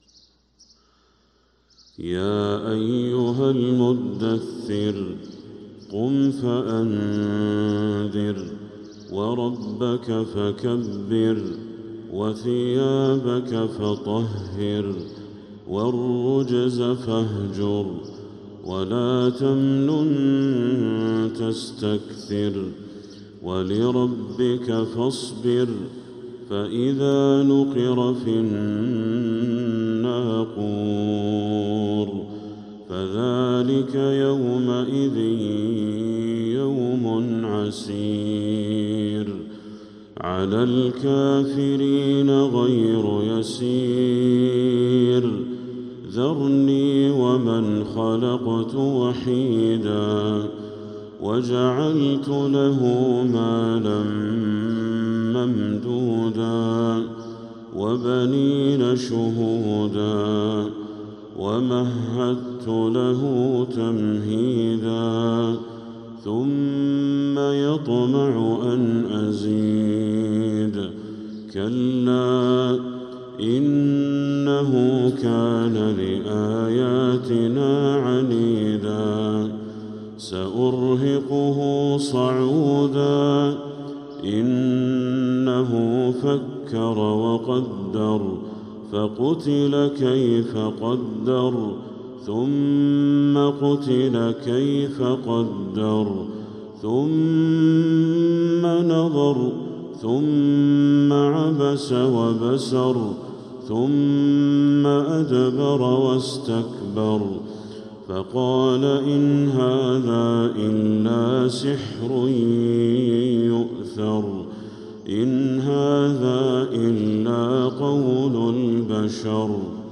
سورة المدثر كاملة | رجب 1446هـ > السور المكتملة للشيخ بدر التركي من الحرم المكي 🕋 > السور المكتملة 🕋 > المزيد - تلاوات الحرمين